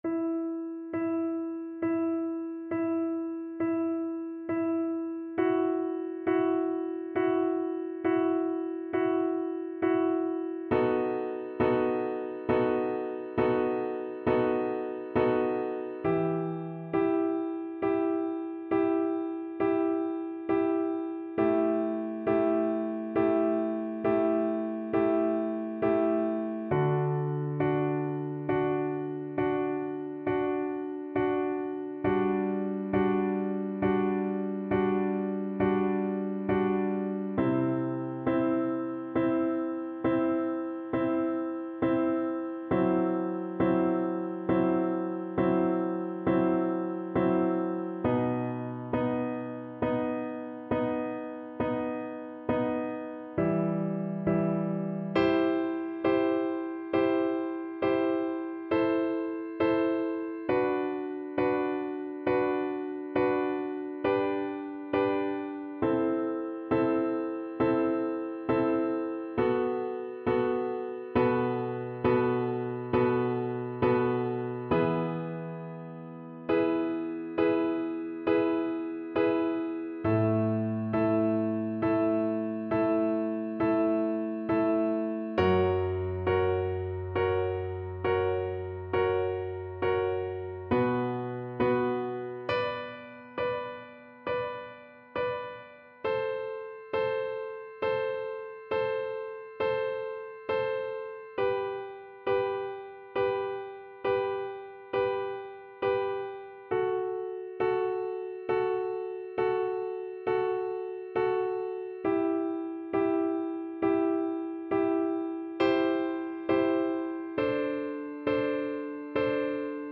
Viola
E minor (Sounding Pitch) (View more E minor Music for Viola )
3/4 (View more 3/4 Music)
Adagio =45
Classical (View more Classical Viola Music)